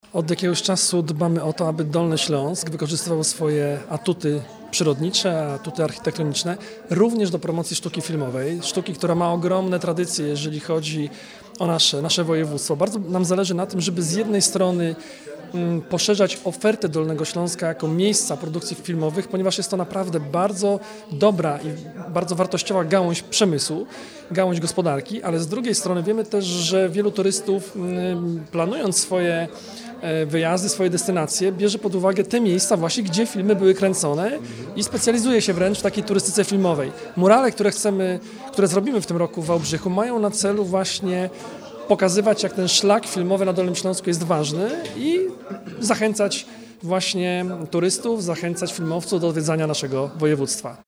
– Sztuka filmowa, cały biznes filmowy to bardzo ważna gałąź gospodarki, bardzo ważny bodziec do rozwoju turystyki. Dolny Śląsk to miejsce, gdzie od dziesiątek lat powstają liczne produkcje filmowe – podkreślił Jarosław Rabczenko, członek Zarządu Województwa Dolnośląskiego.